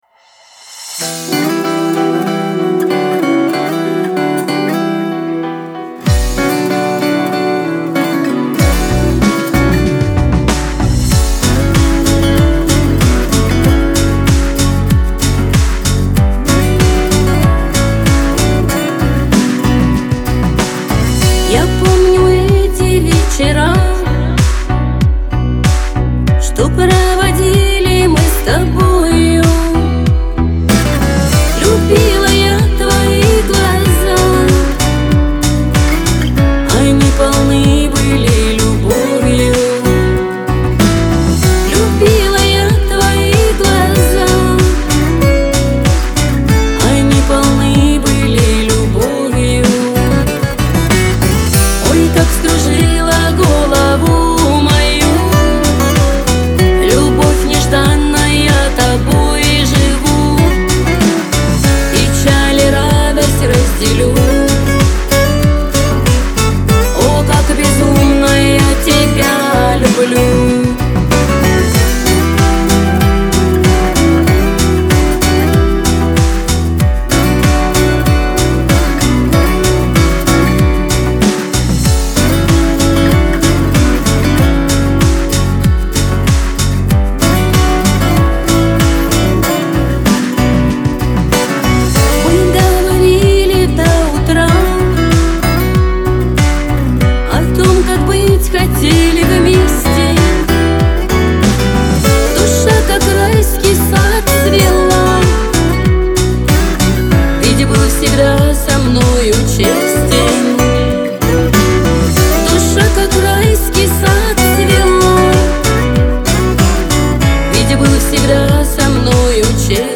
это трек в жанре поп с элементами романтической баллады
Звучание отличается мелодичностью и эмоциональной окраской